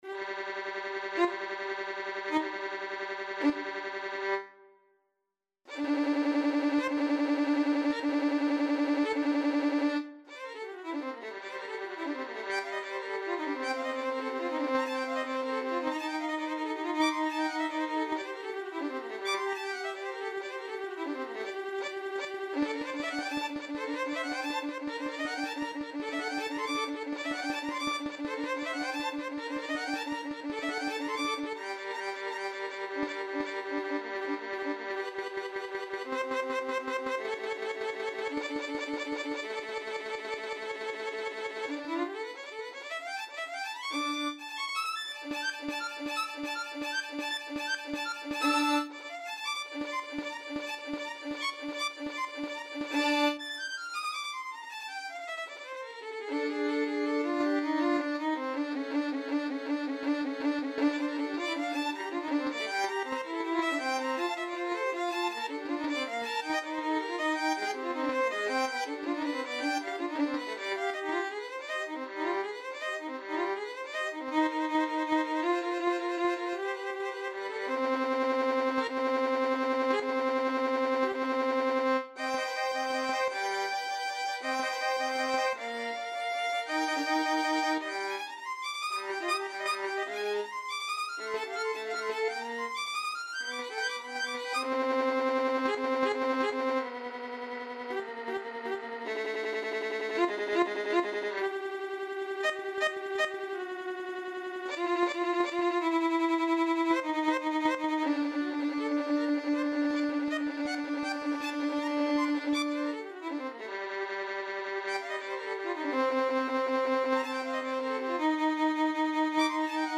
Free Sheet music for Violin Duet
Violin 1Violin 2
Bb major (Sounding Pitch) (View more Bb major Music for Violin Duet )
3/4 (View more 3/4 Music)
III: Presto (View more music marked Presto)
Classical (View more Classical Violin Duet Music)
Vivaldi_summer_3_2VLN.mp3